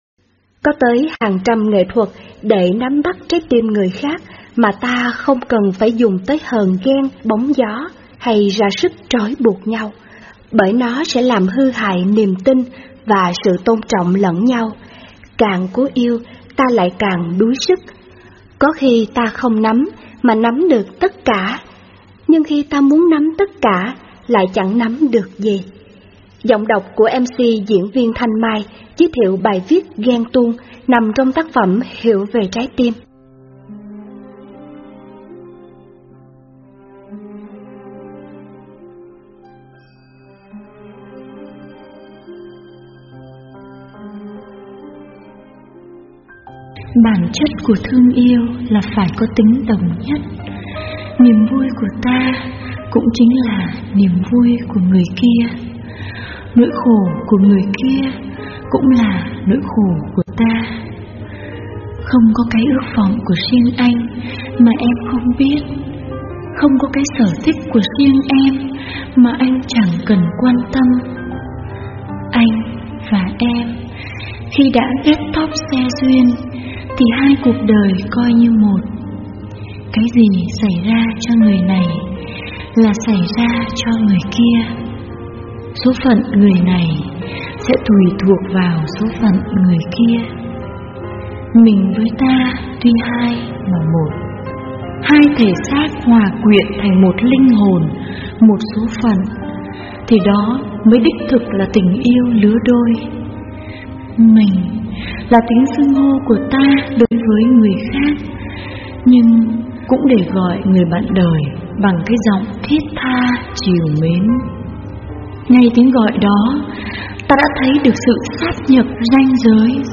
Sách nói mp3